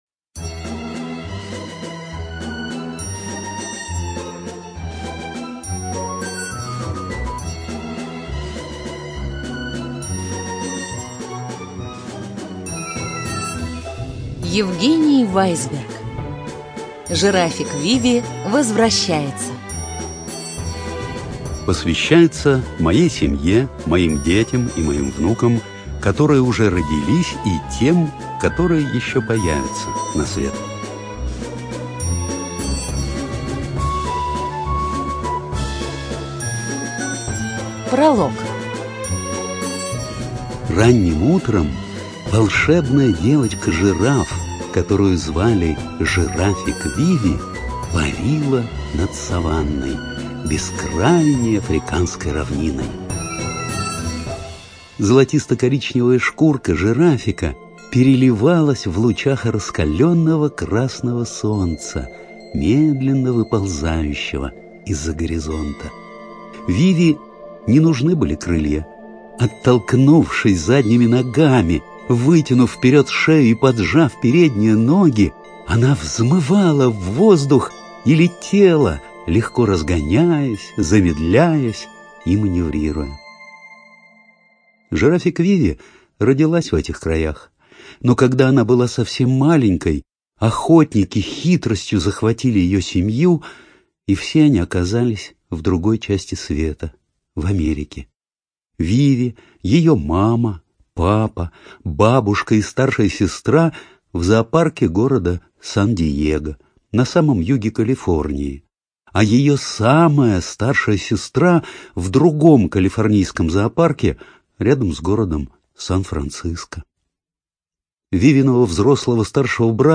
ЖанрРадиоспектакли